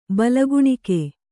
♪ balaguṇike